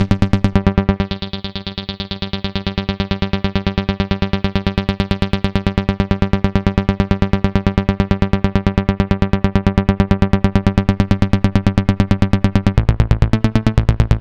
Session 04 - NRG Bass 02.wav